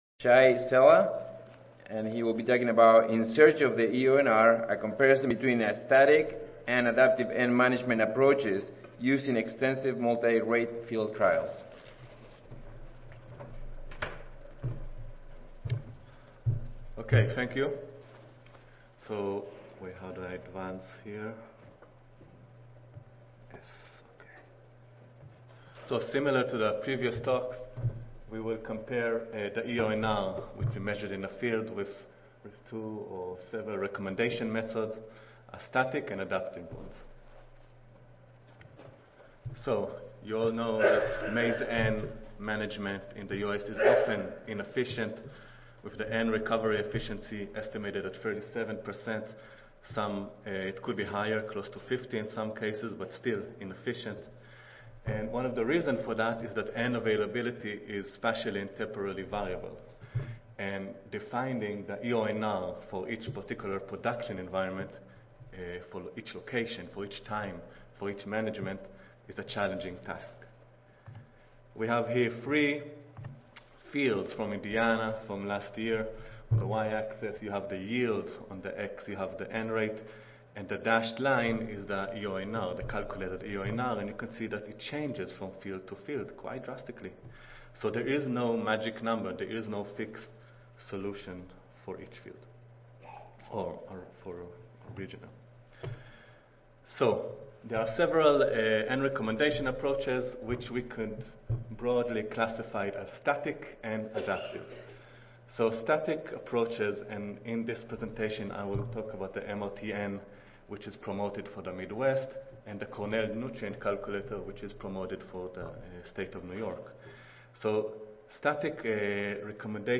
Cornell University Audio File Recorded Presentation